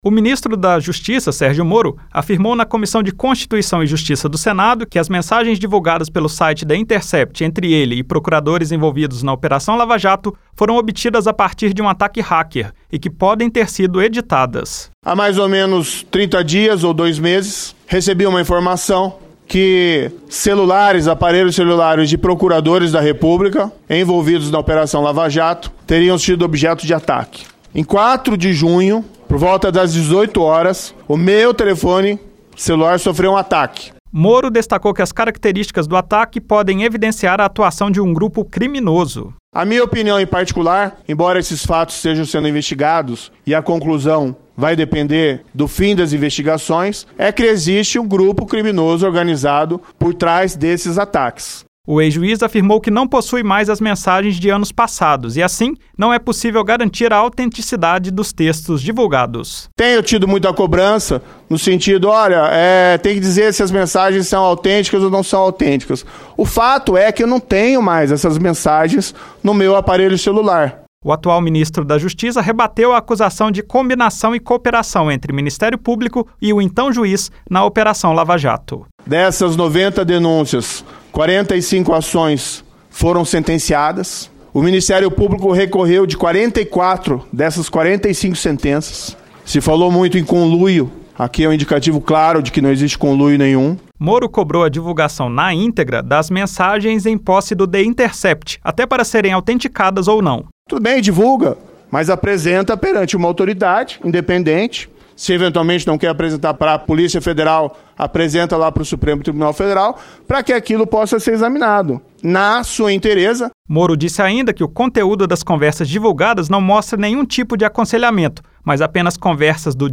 O ministro da Justiça, Sergio Moro, afirmou na Comissão de Constituição e Justiça do Senado que não existiu cooperação entre ele e os procuradores durante a Operação Lava Jato. Moro cobrou que o site The Intercept divulgue a íntegra do material que possui, até para que as mensagens possam ser autenticadas de alguma forma.